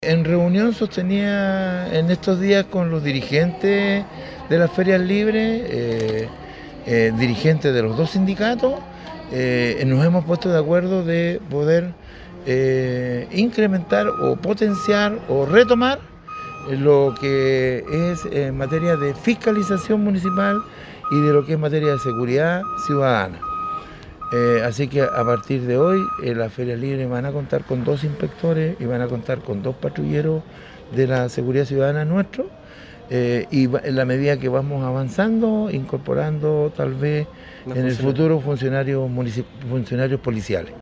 alcalde-Maglio-Cicardini.mp3